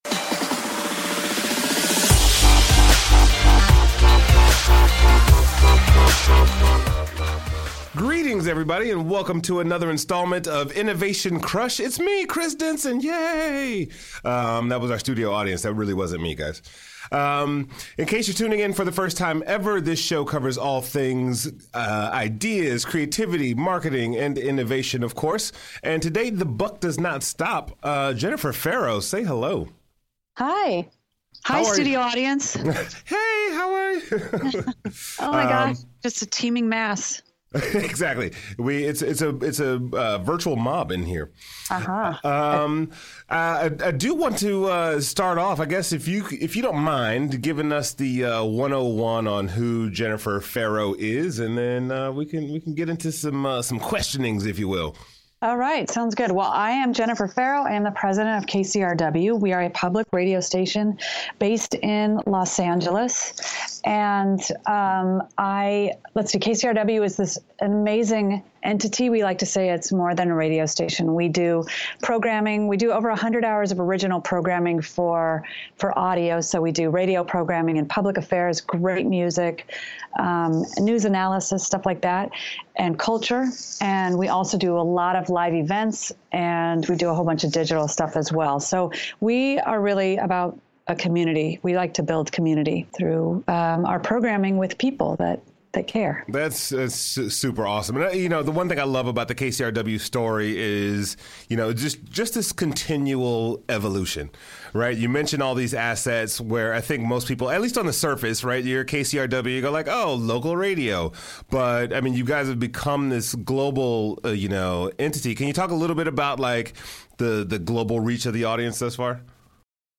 recorded live at the School of Audio Engineering